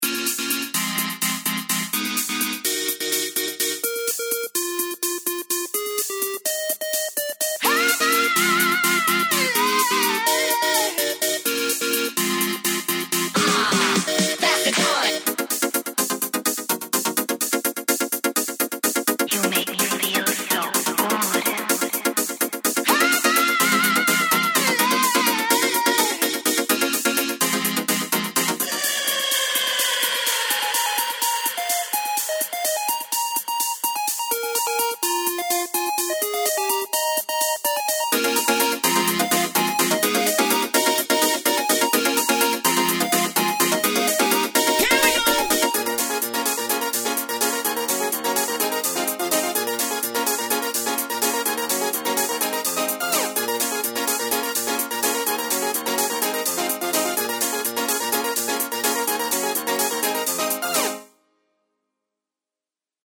The bleeps are made from triangle waves on v-station